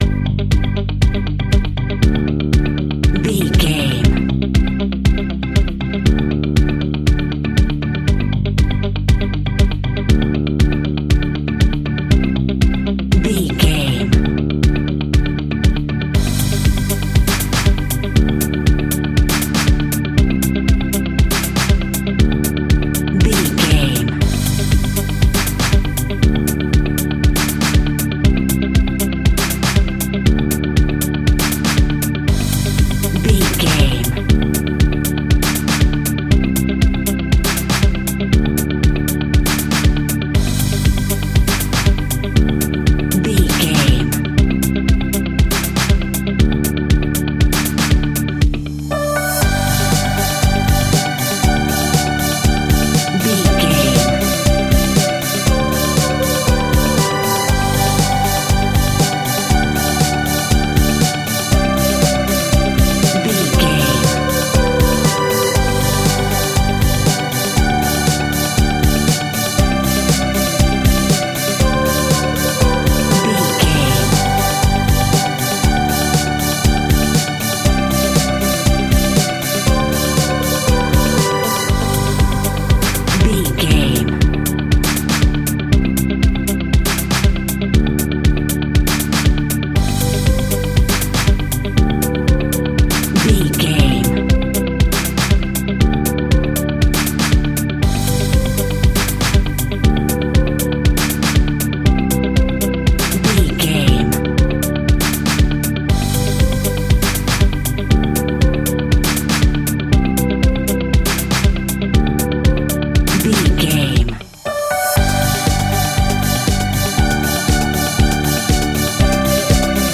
Motivational Business Music.
Ionian/Major
pop rock
indie pop
energetic
uplifting
upbeat
groovy
guitars
bass
drums
piano
organ